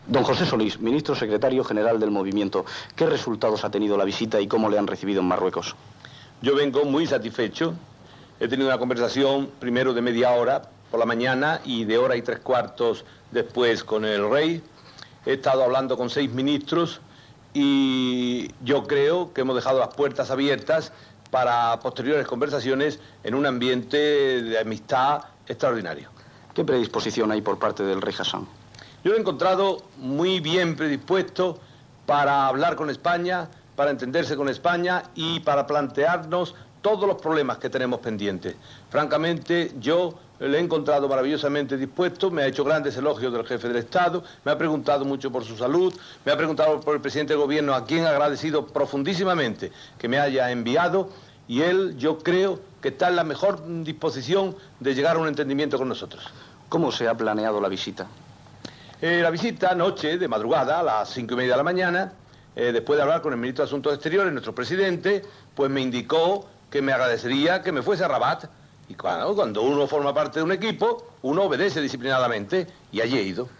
El rei de Marroc, el 6 de novembre de 1975, va convocar els seus subdits a fer la marxa verda cap al Sahara espanyol. Entrevista, pocs dies després, al "ministro secretario general del Movimiento" José Solís després de la seva visita a Marroc per entrevistar-se amb el rei Hassan II
Informatiu